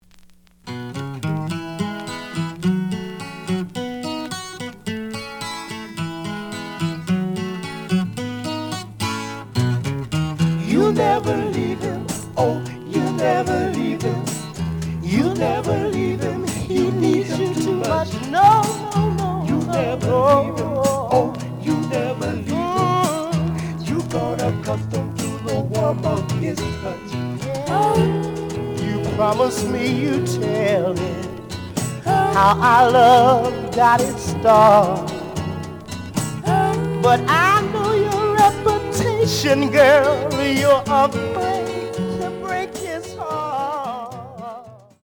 The audio sample is recorded from the actual item.
●Genre: Soul, 60's Soul
Some noise on parts of both sides.